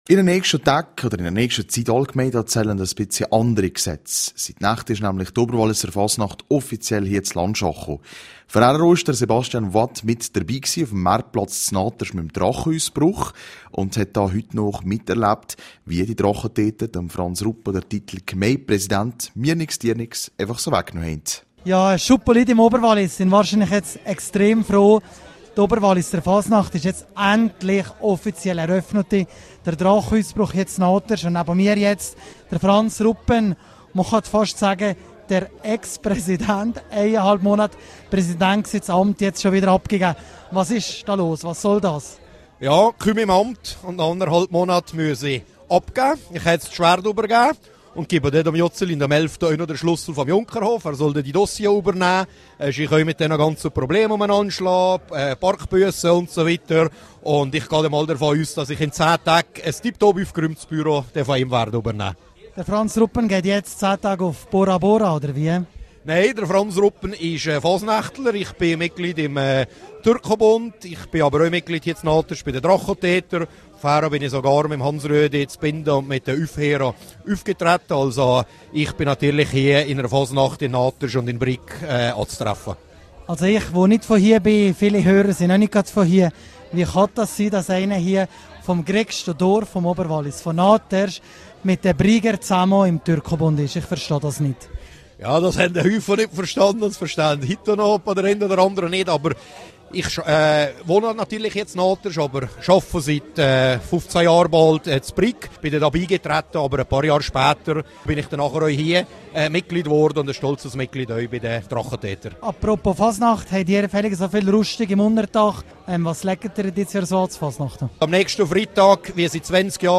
Nicht zu vergessen, all die weiteren närrischen Anlässe im Talgrund, aber auch in den einzelnen Seitentälern./vs Gemeindepräsident Franz Ruppen zum Drachenausbruch (Quelle: rro)